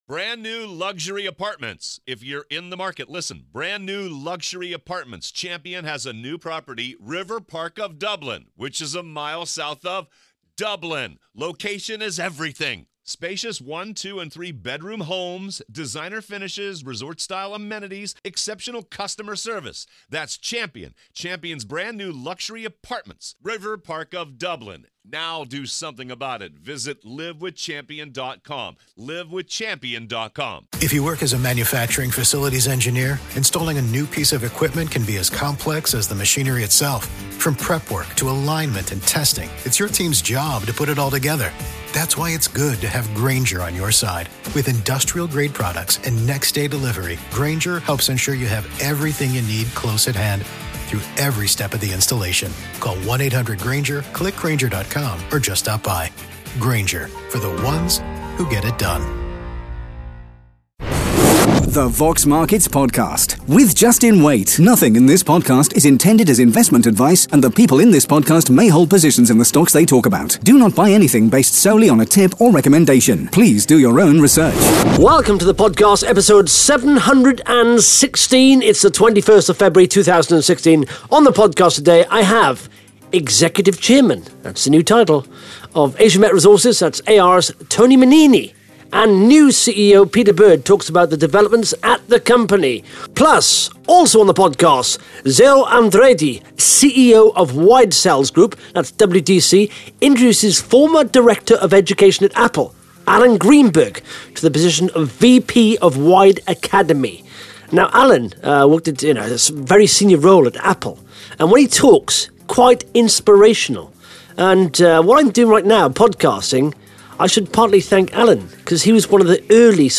(Interview starts at 2 minutes 33 seconds)